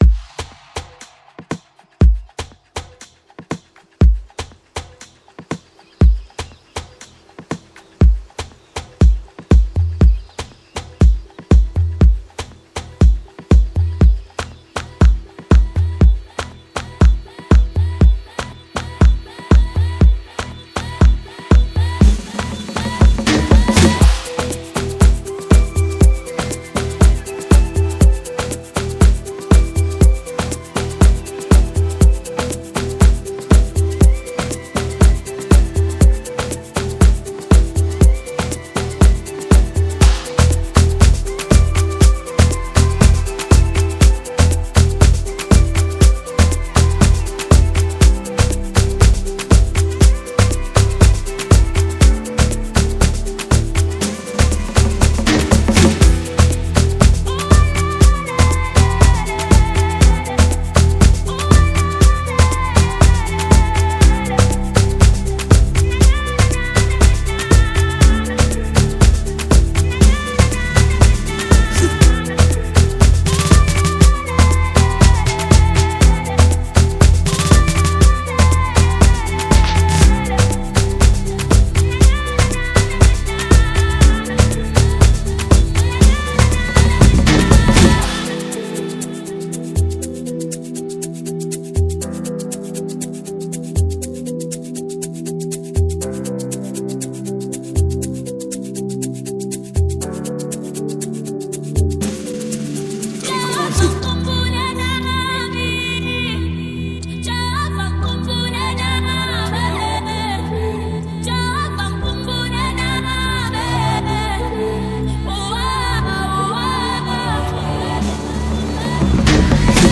Afro Gospel Music
It’s like a big ol’ hug for your soul.